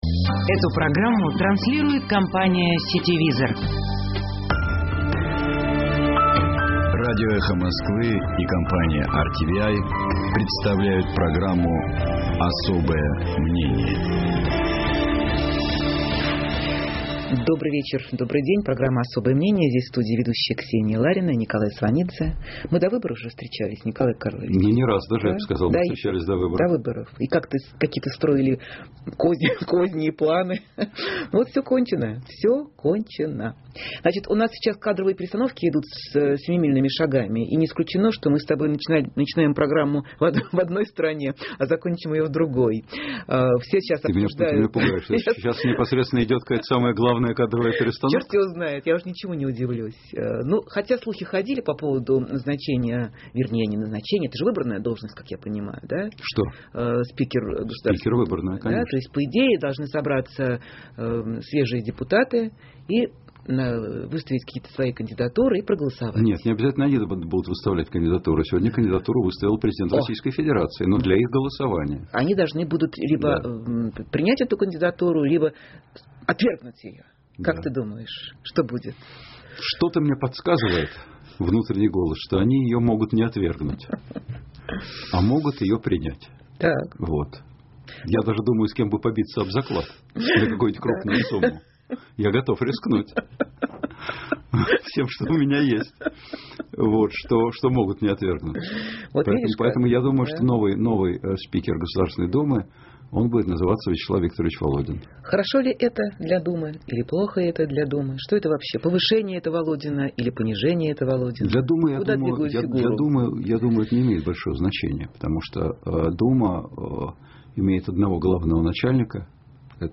В студии ведущая Ксения Ларина.